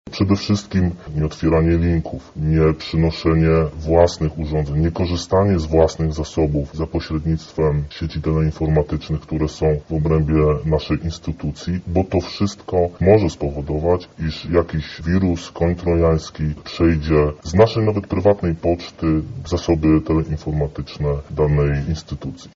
Jak więc uchronić się przed takimi atakami? Na to pytanie odpowiada oficer operacyjny Biura do Walki z Cyberprzestępczością KGP: